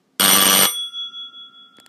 Buzzer.m4a